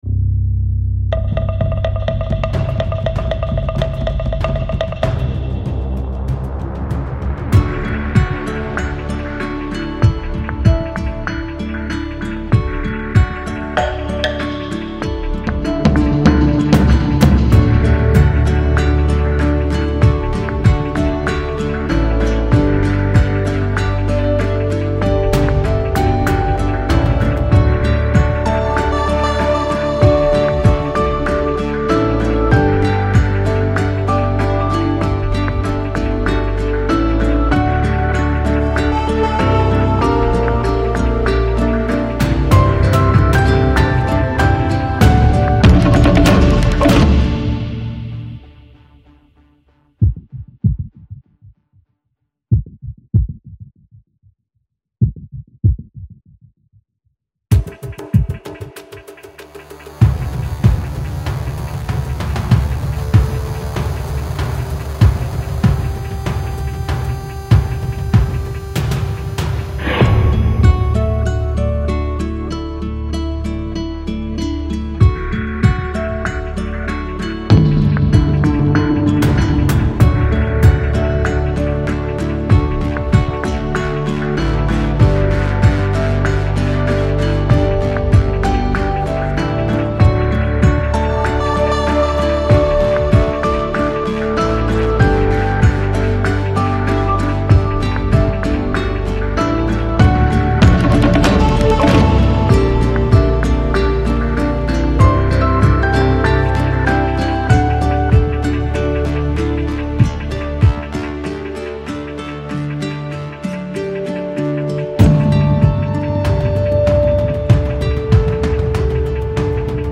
melancolique - vacances - relax - voyage - depaysement